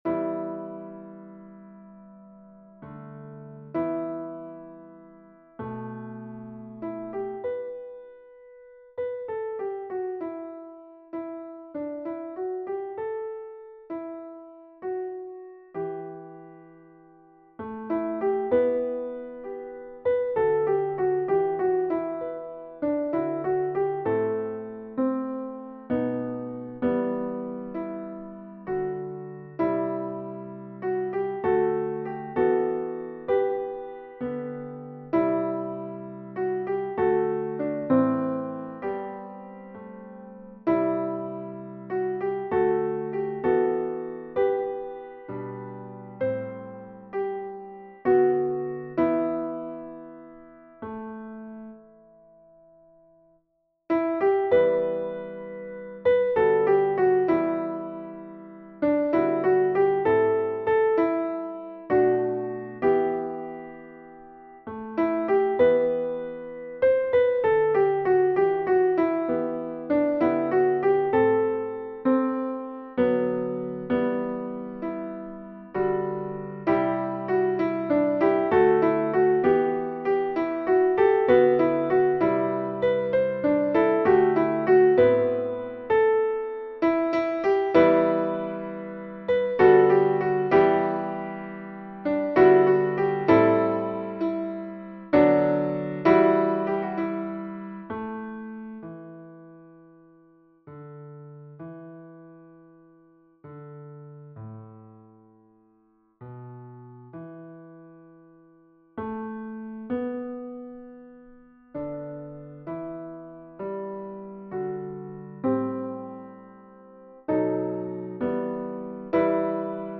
Versions "piano"
Alto